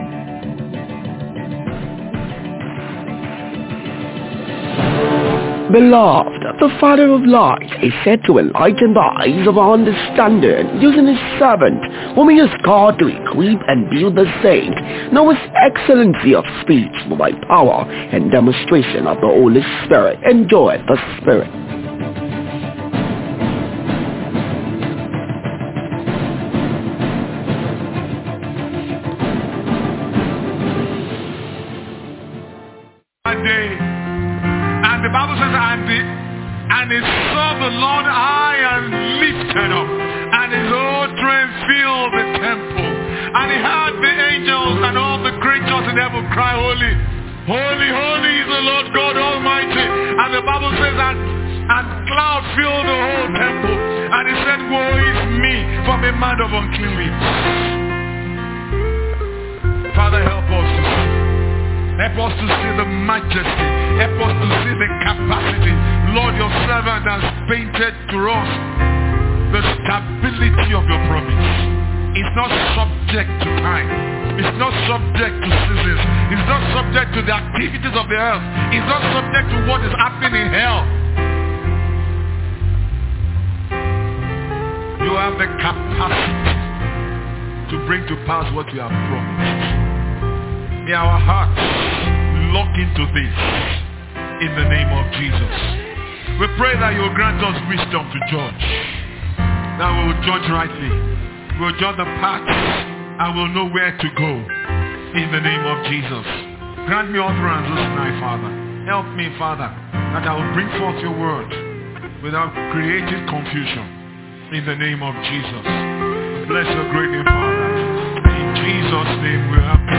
Youth-Convention-Day-2-Evening-Message-2-The-Sure-Word-For-Prophesy.mp3